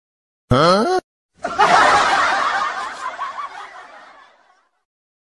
Efek suara Hah tercengang
Kategori: Suara ketawa
Keterangan: Efek suara/ sound effect "Hah tercengang" populer untuk video lucu.
efek-suara-hah-tercengang-id-www_tiengdong_com.mp3